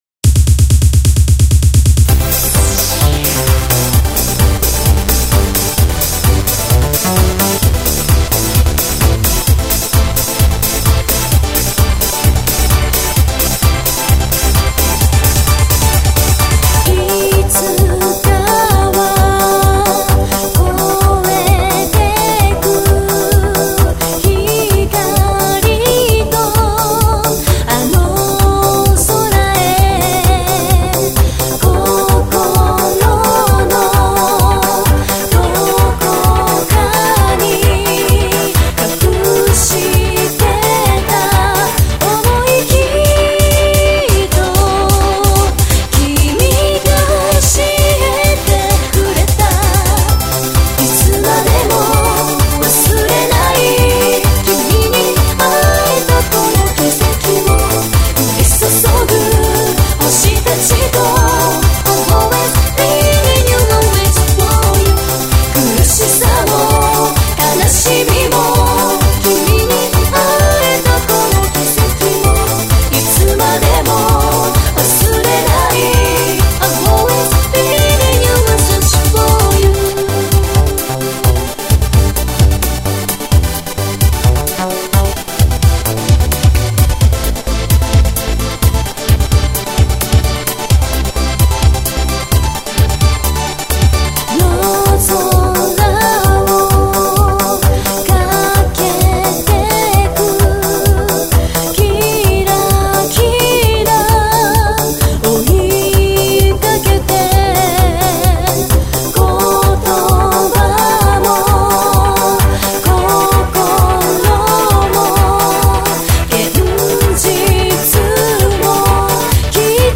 ・元気ロケッツの『Hikari no tabi』をイメージした、フューチャーポップな作品
(すべてPC上で制作)